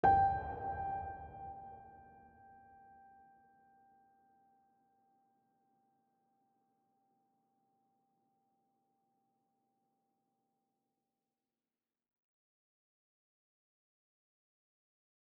GreatAndSoftPiano